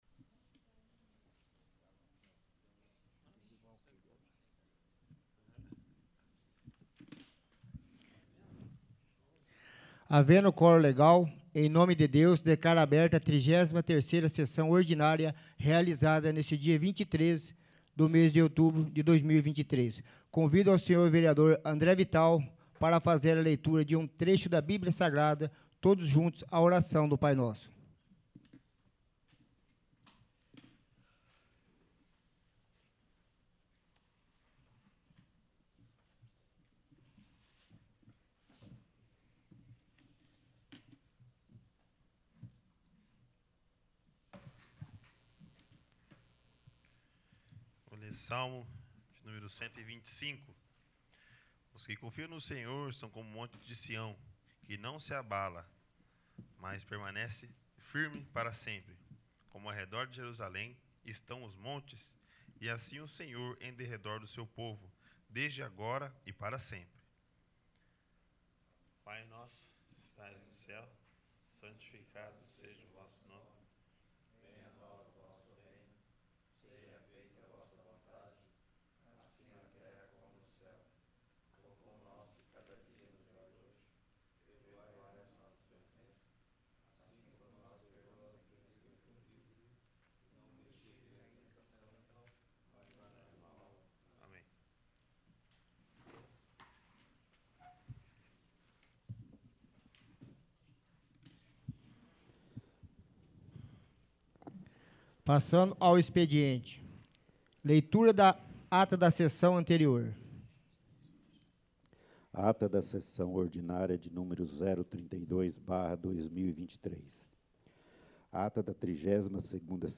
33º. Sessão Ordinária